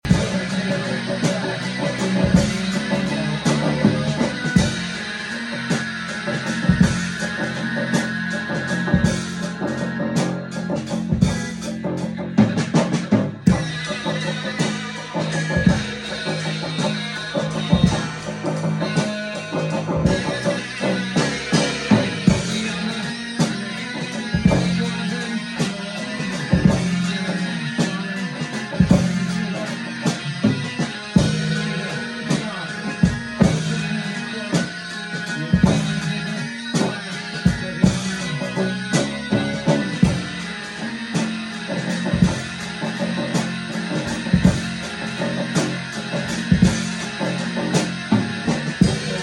(Rehearsal)